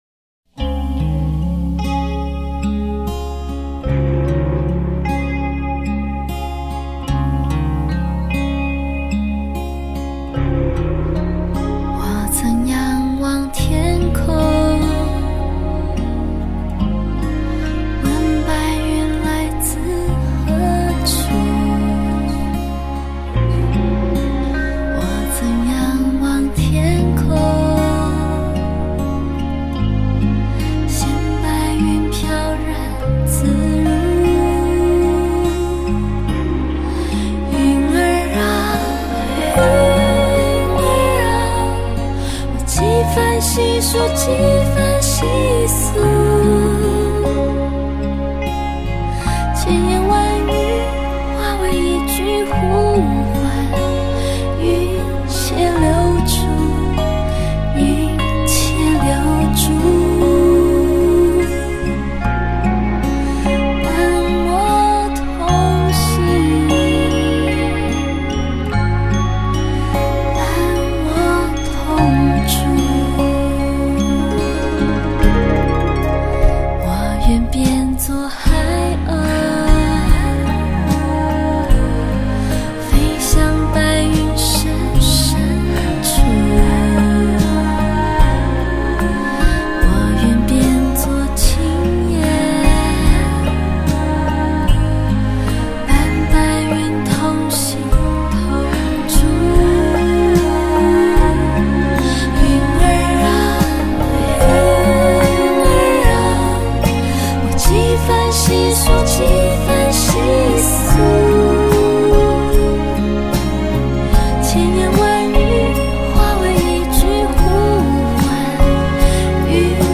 云式唱腔极致作品！